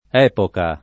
epoca [ $ poka ] s. f.